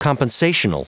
Prononciation du mot compensational en anglais (fichier audio)